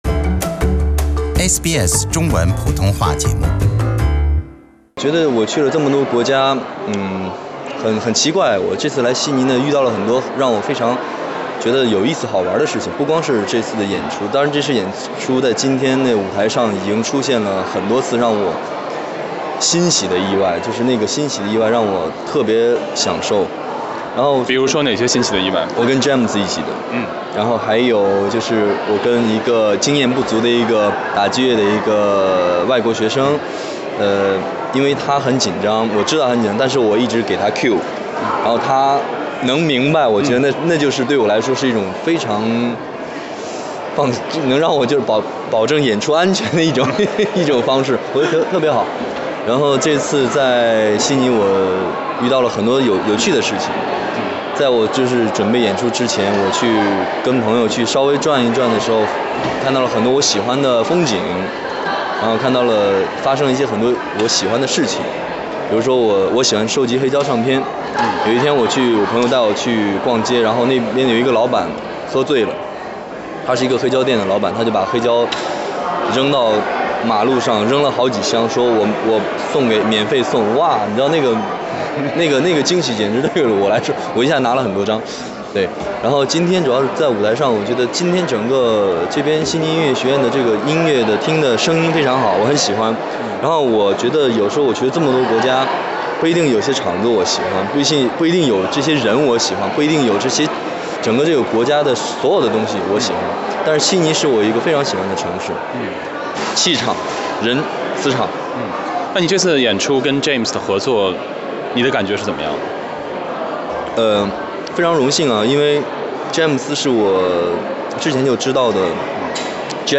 点击收听来自现场的采访报道。